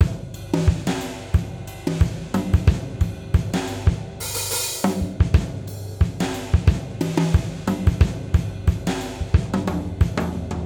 Unison Jazz - 10 - 90bpm.wav